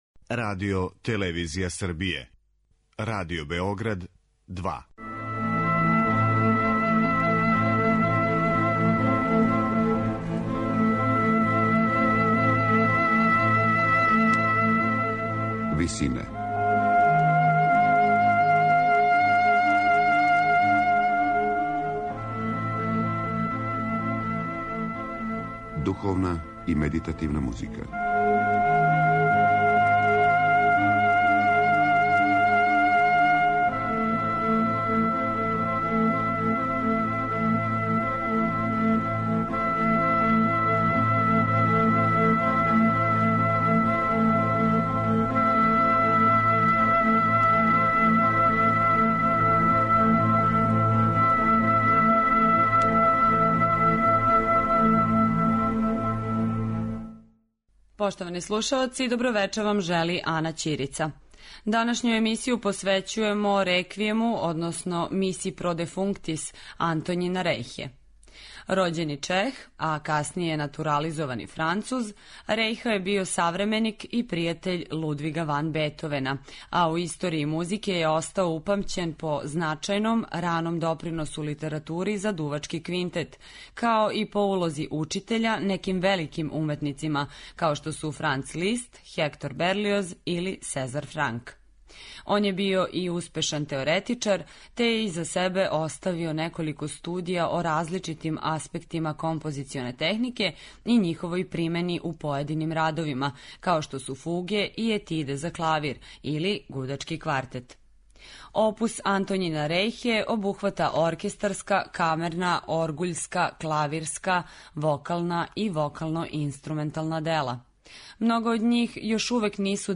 Missa pro defunctis за солисте, оргуље, хор и оркестар, настала је између 1802. и 1808. године, а стилски и временски је позиционирана између монументалних реквијема Моцарта и Берлиоза...